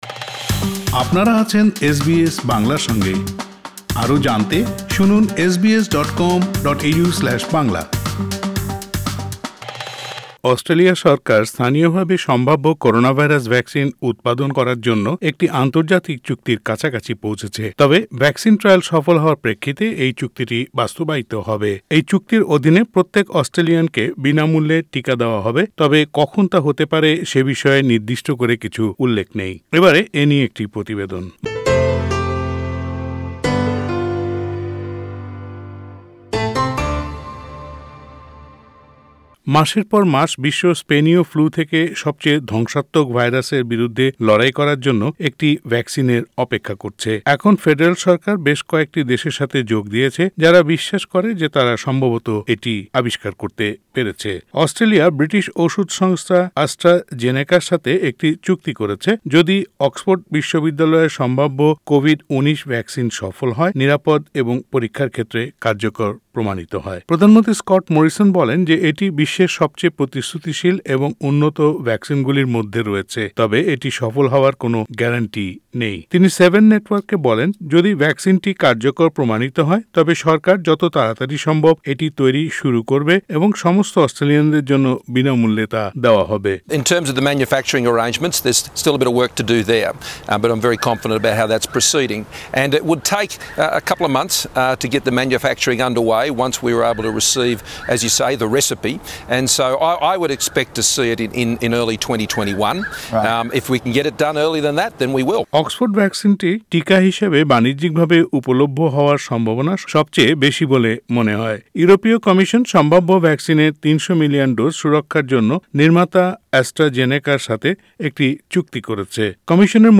উপরের প্রতিবেদনটি শুনতে অডিও প্লেয়ারের লিংকটিতে ক্লিক করুন।